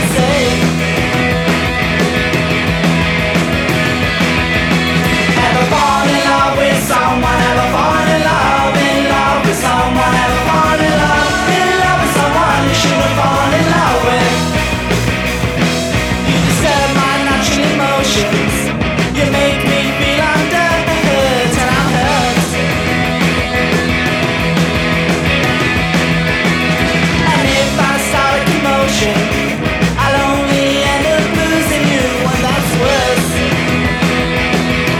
Жанр: Панк